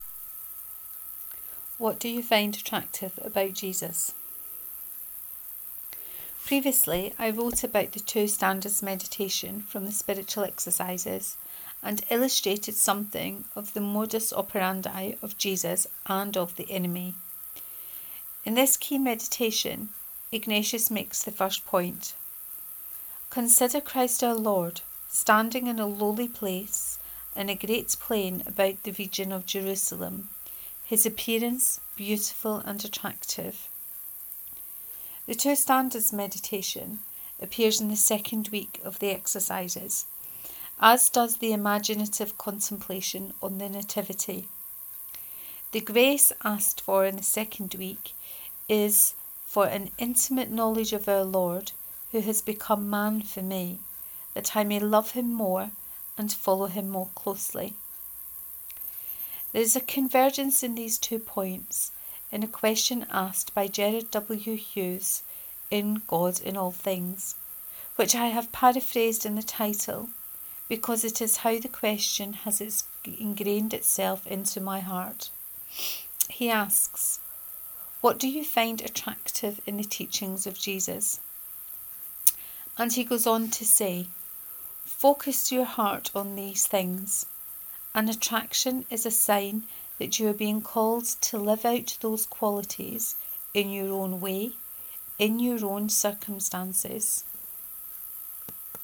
What do you find attractive about Jesus? 1: Reading of this post.